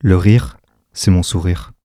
20s A2WS francais FR-AV2 FRAV2 french human male sound effect free sound royalty free Memes